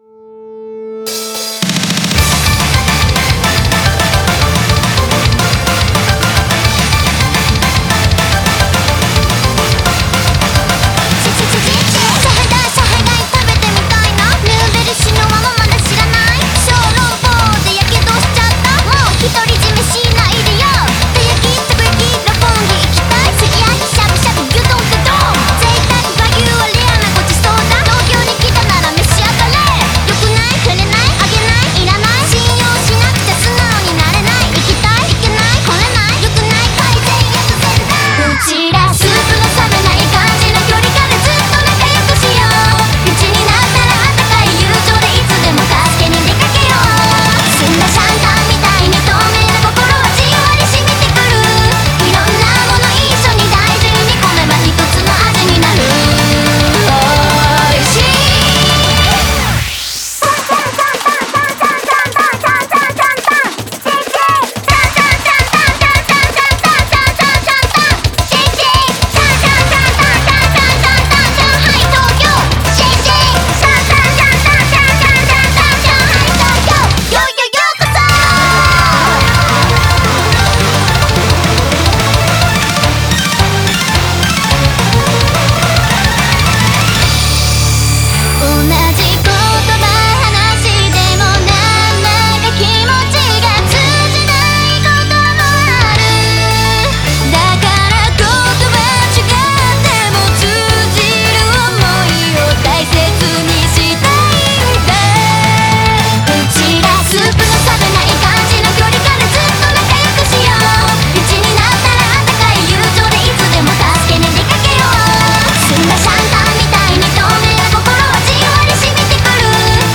BPM107-215
Audio QualityPerfect (High Quality)